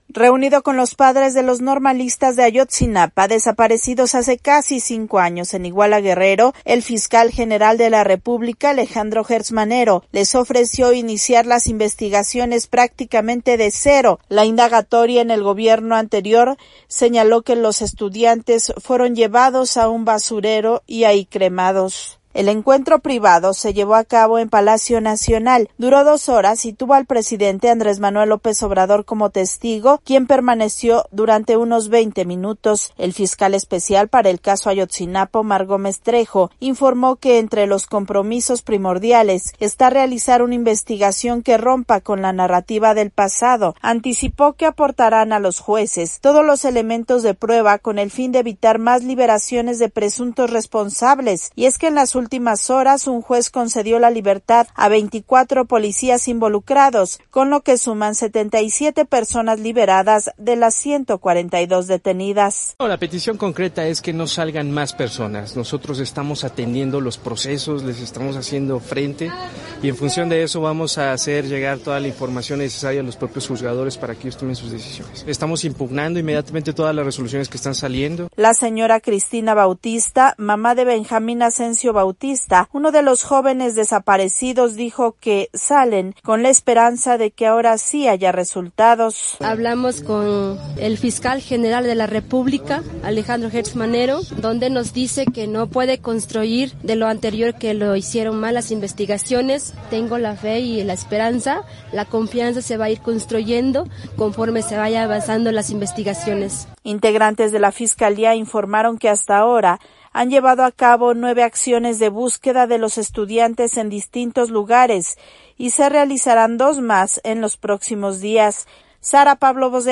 VOA: INFORME DESDE MEXICO